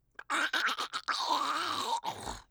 Zombie Voice Pack - Free / Zombie Death
zombie_death_010.wav